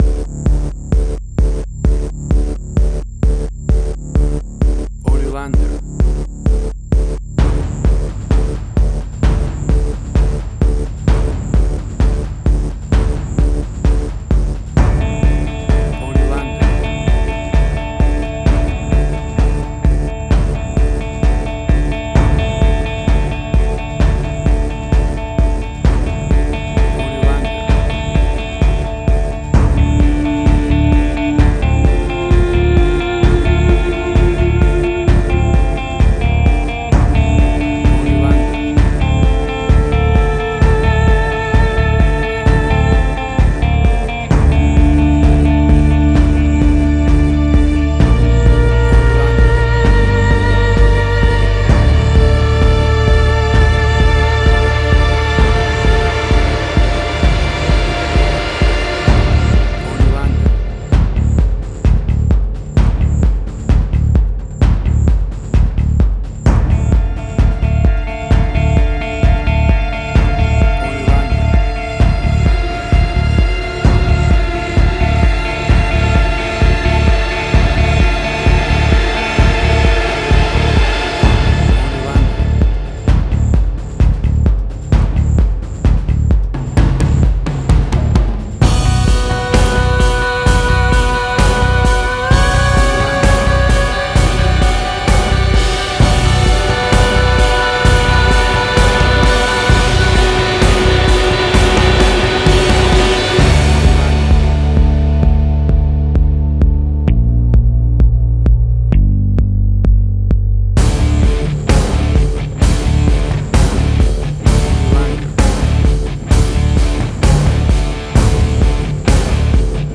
Cinematic Industrial Sci-fi.
Tempo (BPM): 130